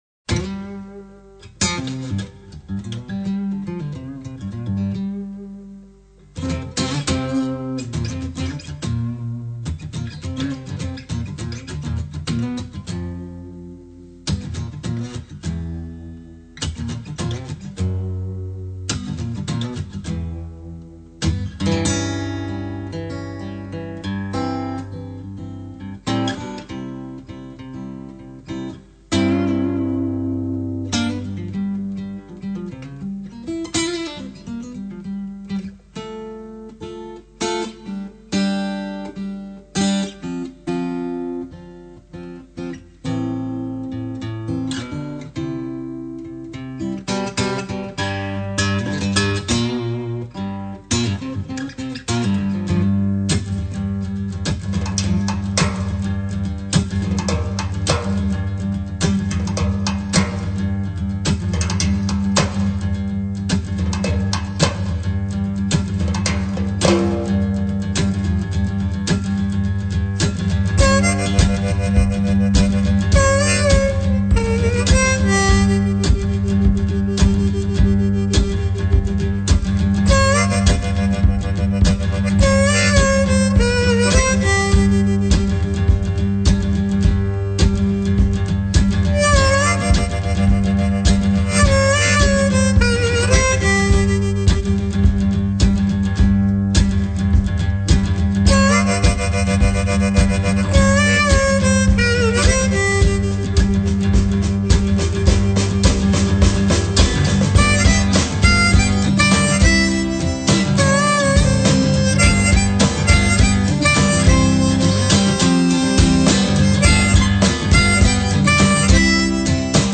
acoustic guitar, harmonica, vocals
drums and percussion
Recorded live in the studio.